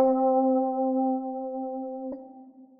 Rhodes - Bread.wav